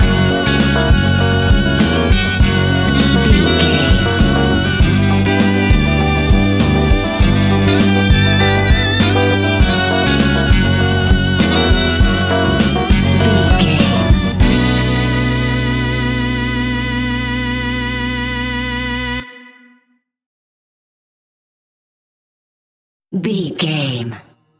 Aeolian/Minor
flamenco
groove
maracas
percussion spanish guitar